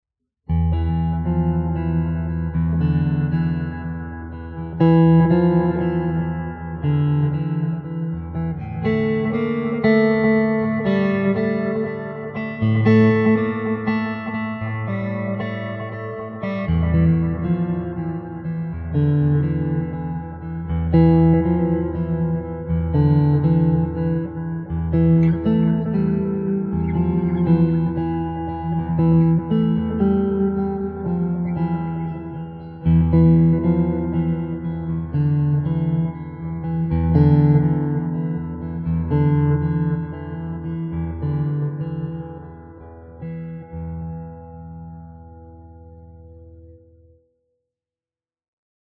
~ 380 ms
Black Strat > ECHOLTD 2° > Amp